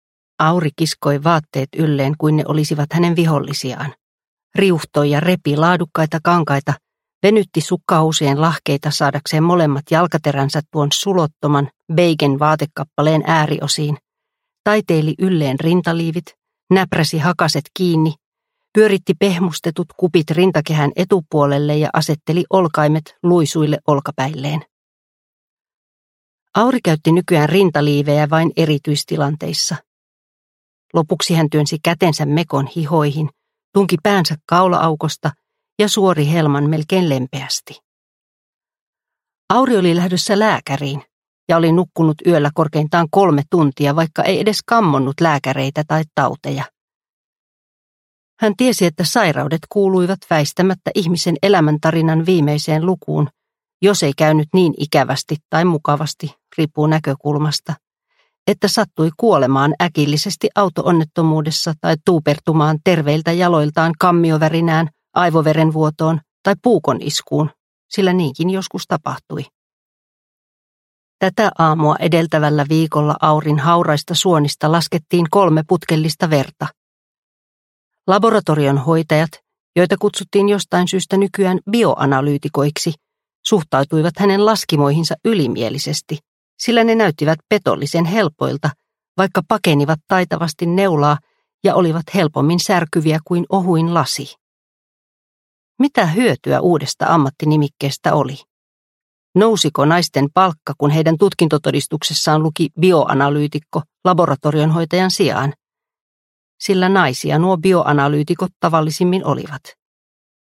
Ei saa elvyttää – Ljudbok – Laddas ner